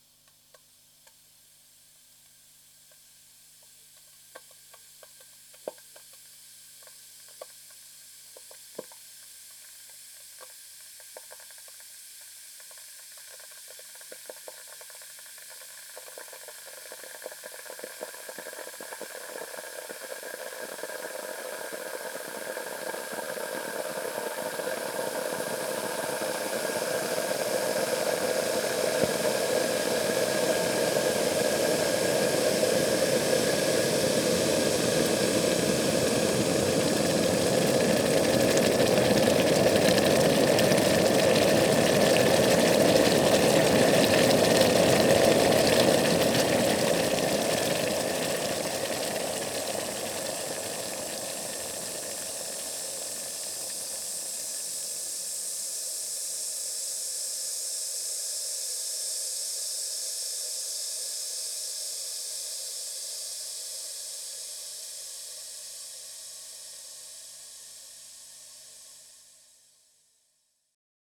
household
Water Boiler Noise